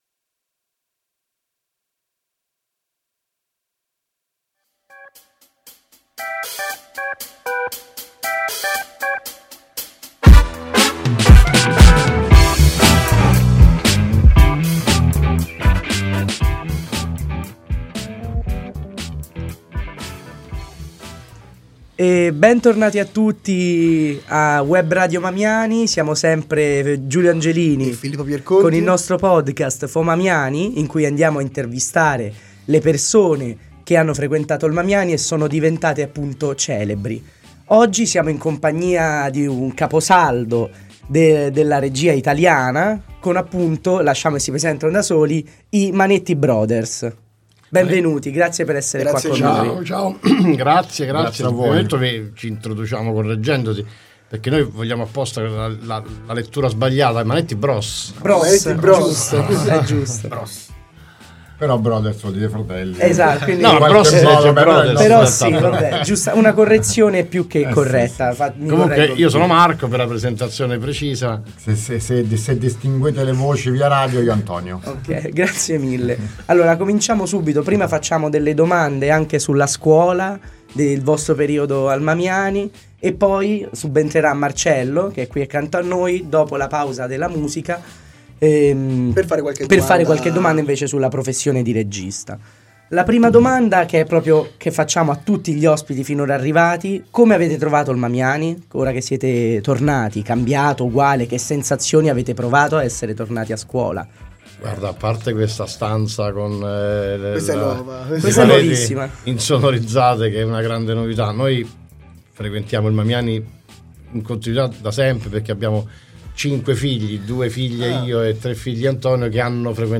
Radio Mamio – Intervista ai Manetti bros | Radio Città Aperta
Manetti-Bros-intervista-final-cut.mp3